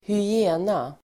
Uttal: [²hy'e:na]